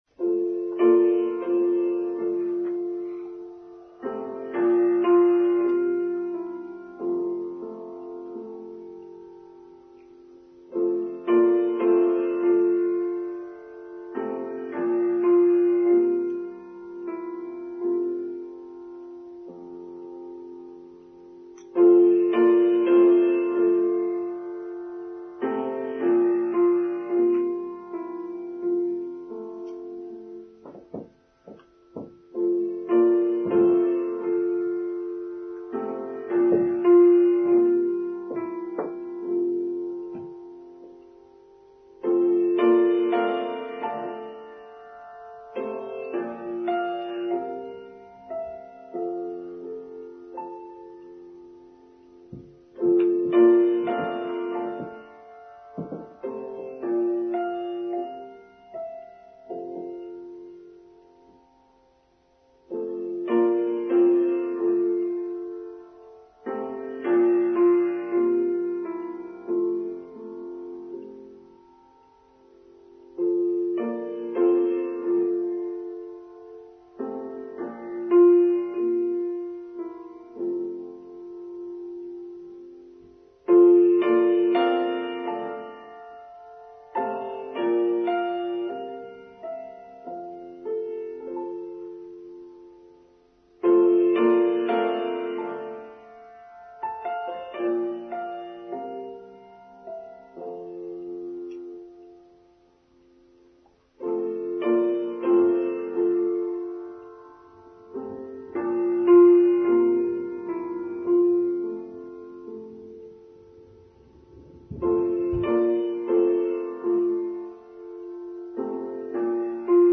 Towards Inclusivity: Online Service for Sunday 9th July 2023